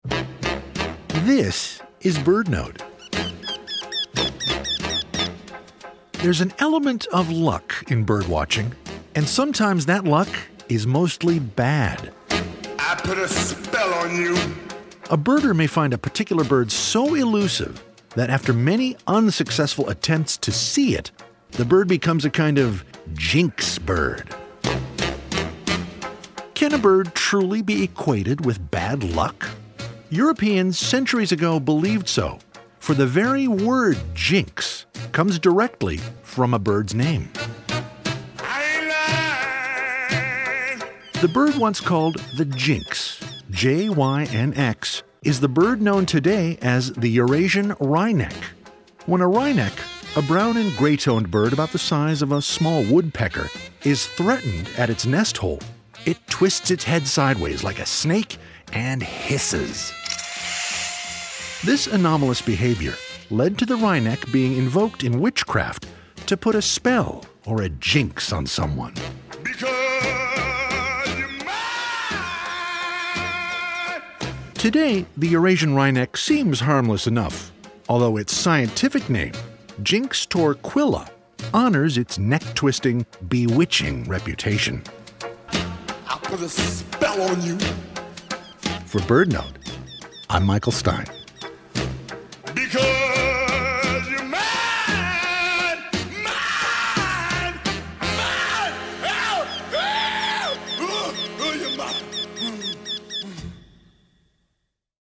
BirdNote is sponsored locally by Chirp Nature Center and airs live every day at 4 p.m. on KBHR 93.3 FM.